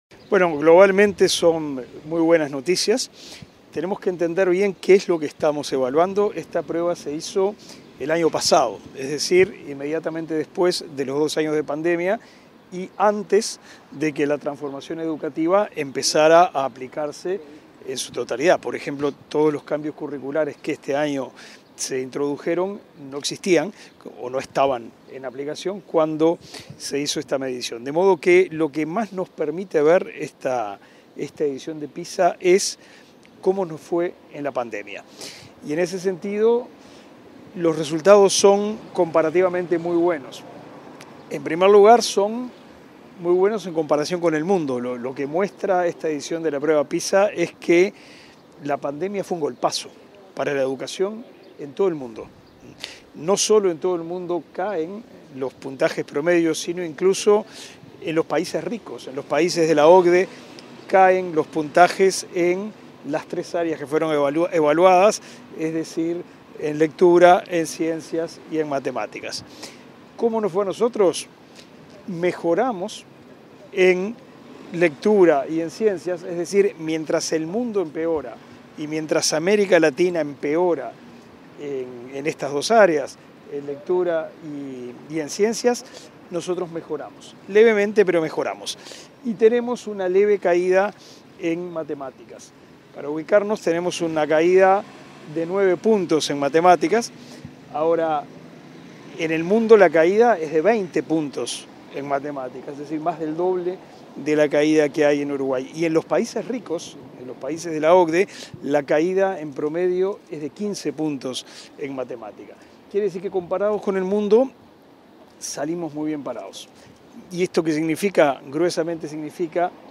Declaraciones del ministro de Educación y Cultura, Pablo da Silveira 05/12/2023 Compartir Facebook X Copiar enlace WhatsApp LinkedIn Tras la presentación de los resultados de las pruebas PISA 2022, el ministro de Educación y Cultura, Pablo da Silveira, realizó declaraciones a la prensa.